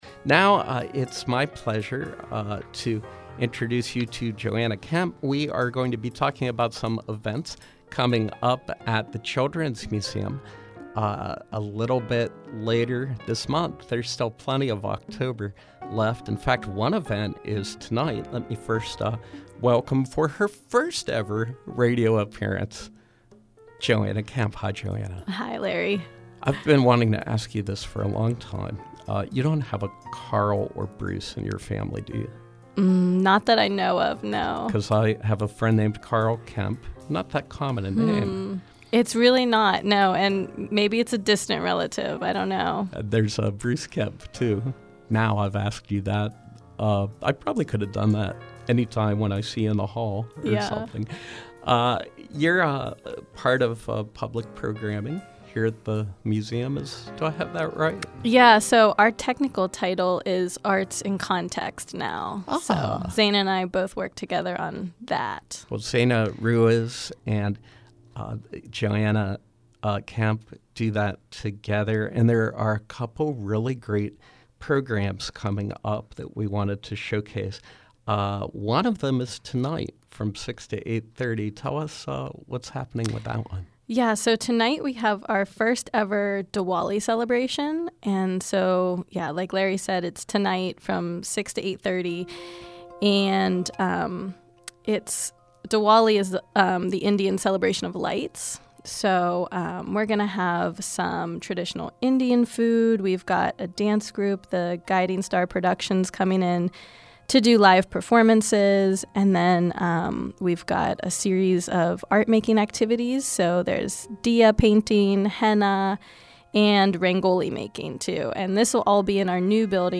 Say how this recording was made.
In Studio Pop-up: The Children’s Muesuem